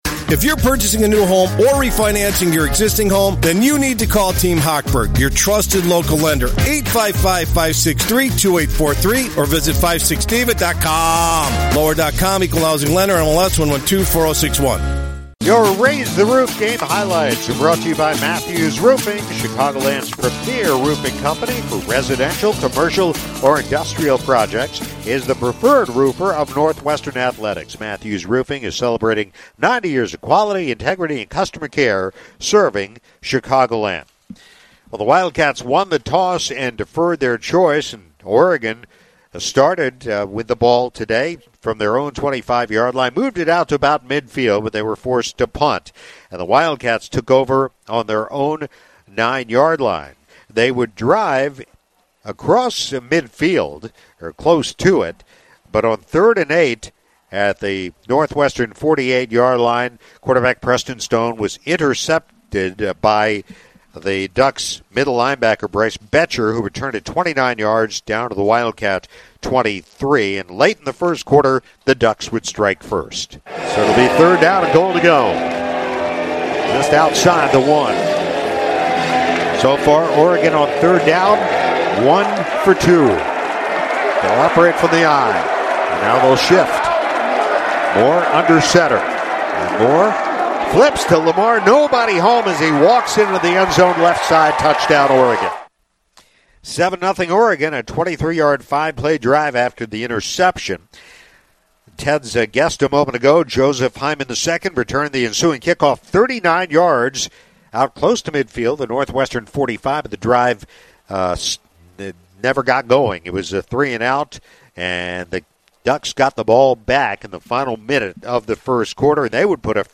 Highlights: Northwestern 42 – Western Illinois 7 – 9/5/25